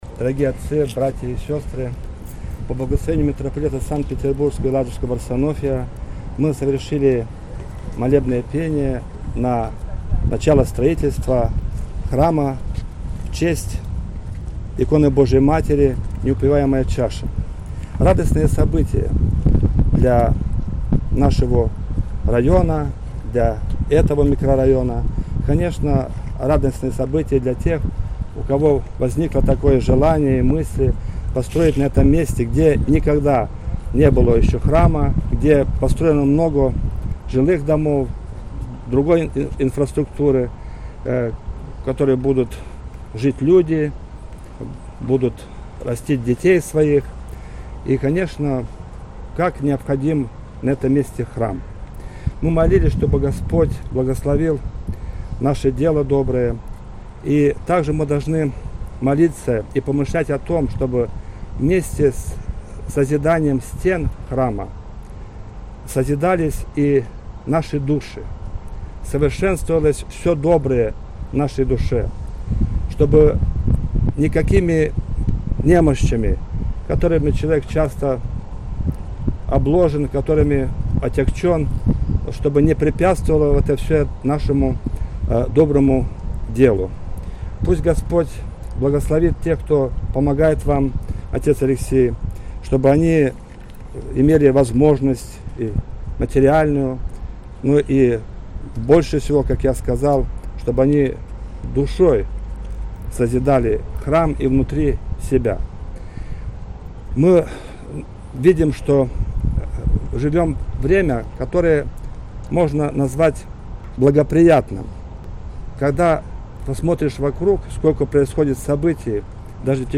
Молебен.mp3